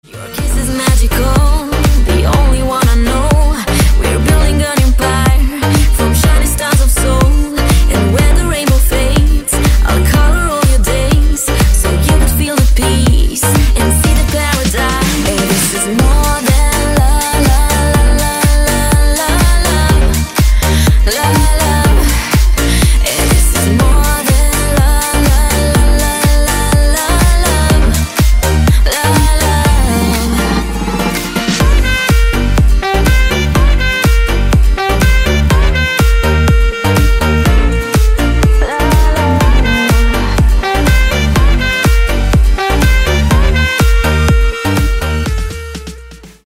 • Качество: 192, Stereo
женский вокал
dance
Electronic
EDM
club